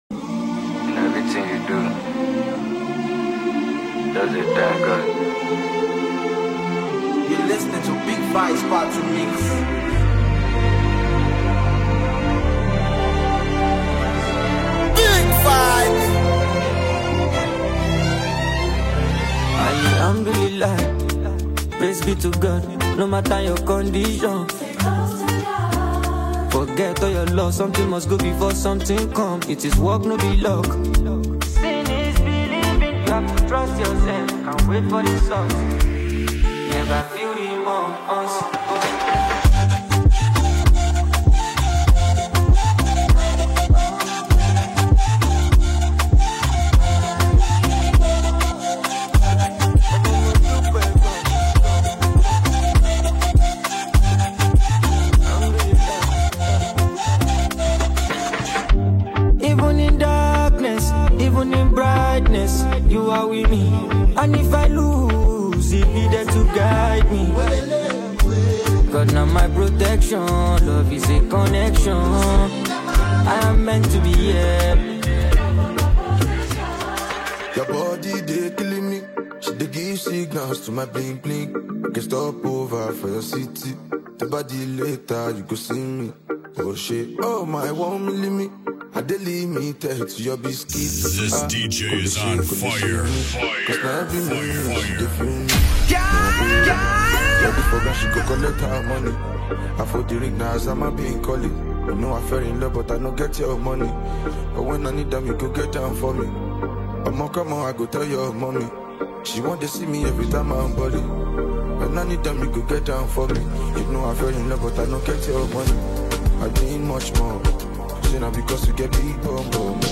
a high‑energy blend designed to keep the dance floor alive.